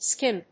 /skímp(米国英語)/